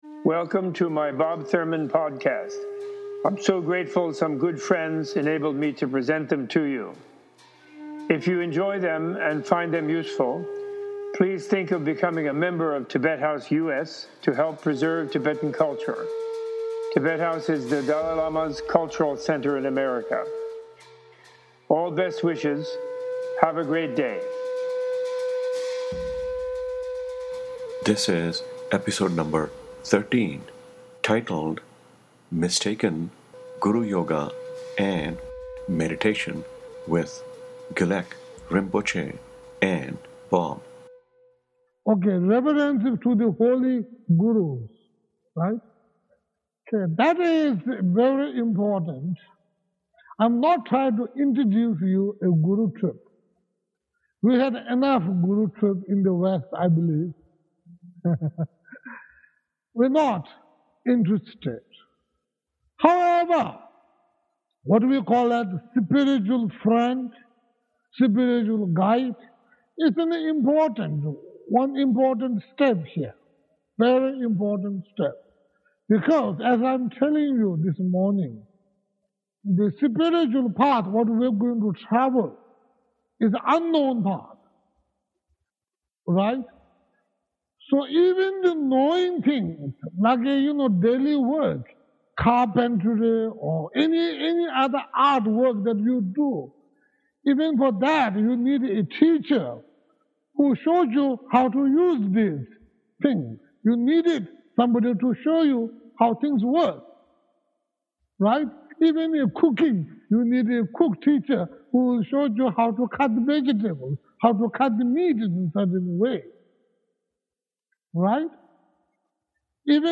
Introducing the Vimalakīrti Nirdeśha Sūtra, Gelek Rimpoche and Professor Thurman discuss mistaken relationships with Buddhist gurus and books, particularly in the West. They offer guidance for identifying a good guru and for maintaining a healthy relationship with the guru. In monastic Buddhism, the abbot is not a big boss and obedience is not a big virtue for the Buddhist monk or mendicant.